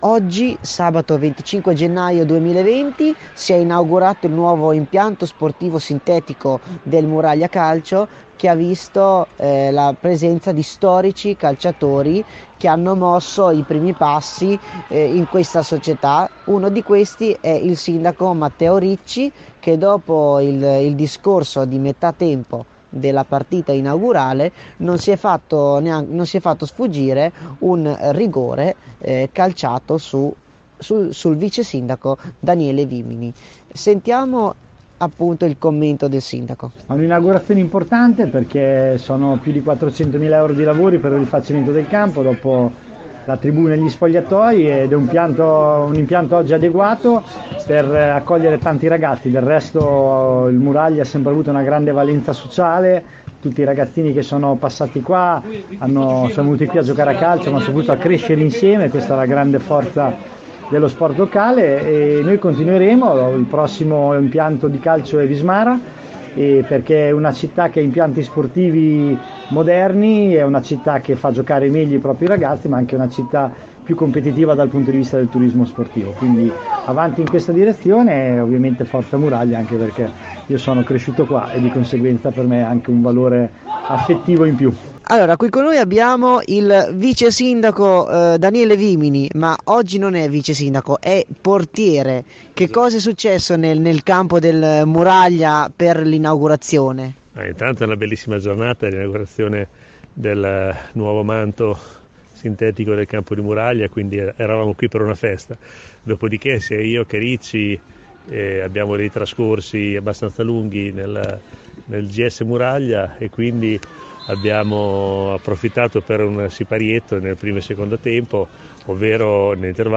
E’ stato inaugurato questo pomeriggio il nuovo impianto sportivo di Muraglia in erba sintetica di ultima generazione, durante l’intervallo tra primo e secondo tempo tra Muraglia e Torre San Marco, con il saluto del sindaco Matteo Ricci e delle autorità presenti. Le interviste ai nostri microfoni a: Matteo Ricci Sindaco di Pesaro e Daniele Vimini Vice Sindaco di Pesaro.